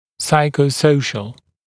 [ˌsaɪkə(u)’səuʃl][ˌсайко(у)’соушл]психосоциальный